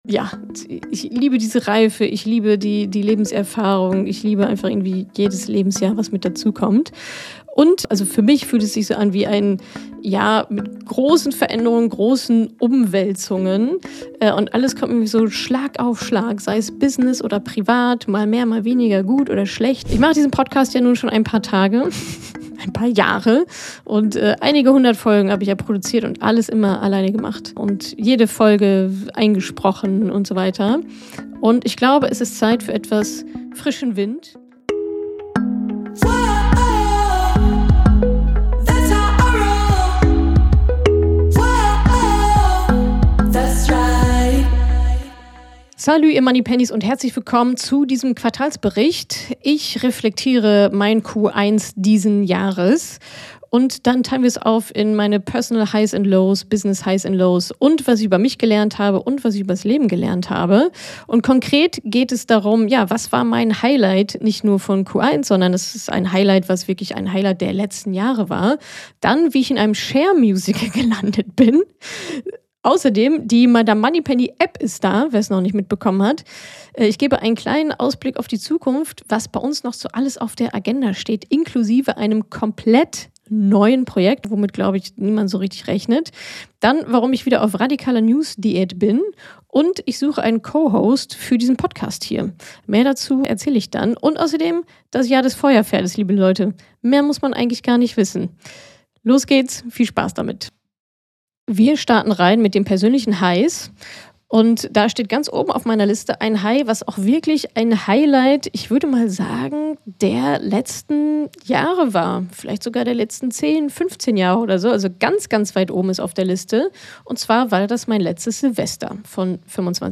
In meinem Lieblingsformat Money Stories spreche ich mit den mutigen Frauen, die den Schritt ins Mentoring gewagt haben, um ihre Finanzen und ihr Money Mindset einmal komplett umzukrempeln und neu aufzusetzen.